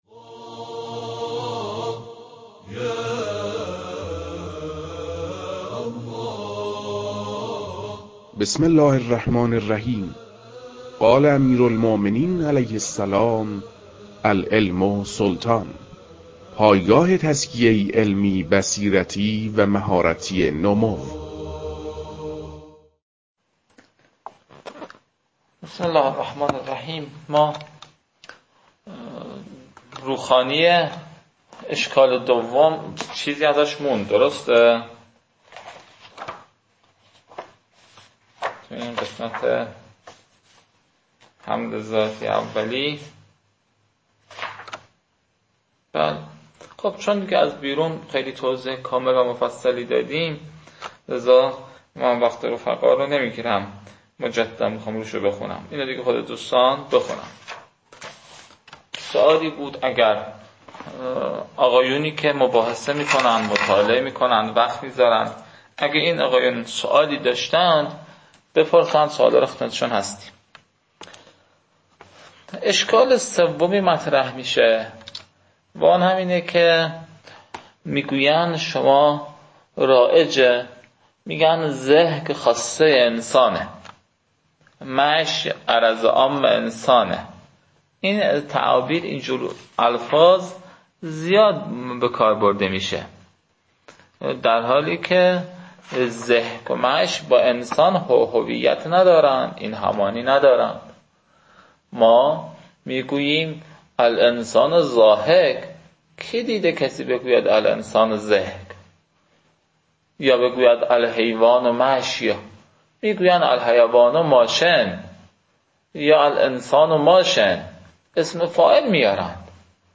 در این بخش، کتاب «منطق مظفر» که اولین کتاب در مرحلۀ شناخت علم منطق است، به صورت ترتیب مباحث کتاب، تدریس می‌شود.